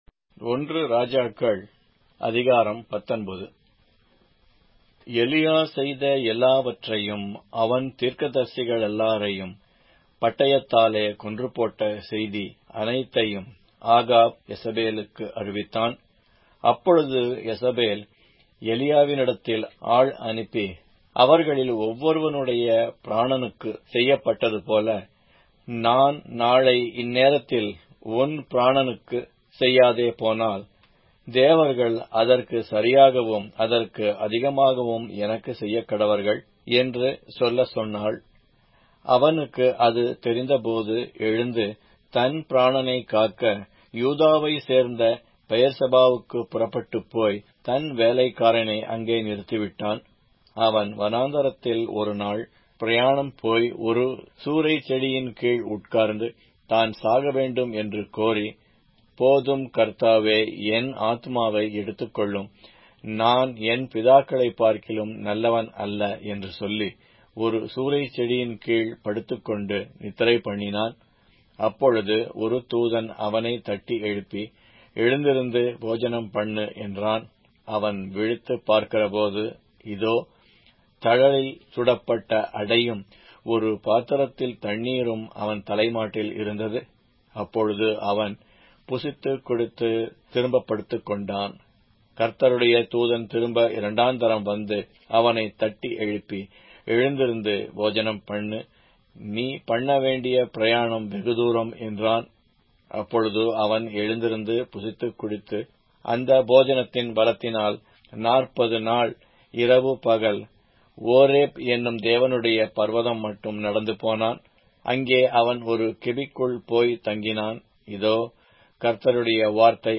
Tamil Audio Bible - 1-Kings 3 in Irvpa bible version